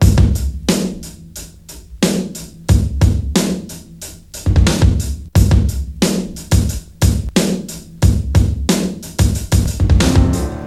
• 90 Bpm HQ Hip-Hop Drum Beat C Key.wav
Free breakbeat sample - kick tuned to the C note. Loudest frequency: 1028Hz
90-bpm-hq-hip-hop-drum-beat-c-key-VxX.wav